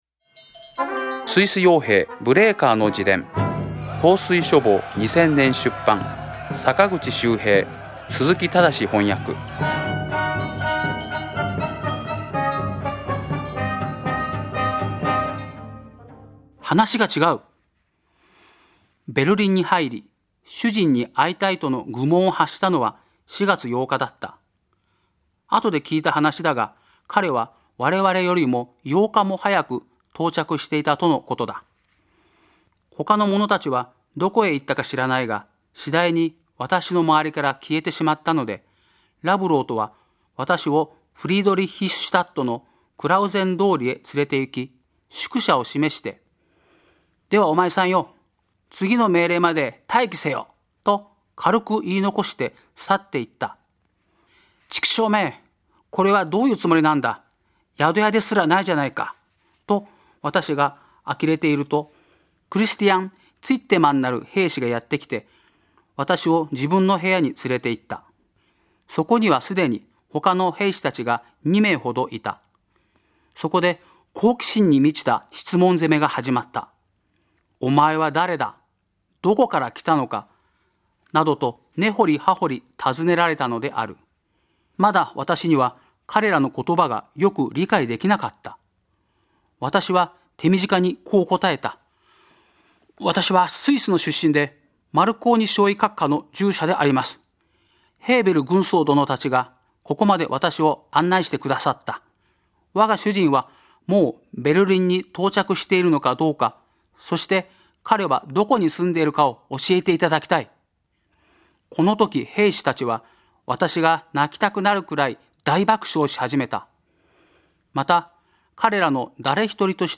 朗読『スイス傭兵ブレーカーの自伝』第48回